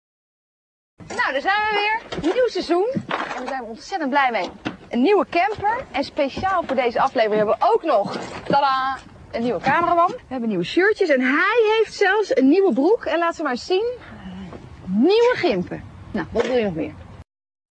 Deze website gaat over de opvallendste variëteit van het Algemeen Nederlands: het Poldernederlands.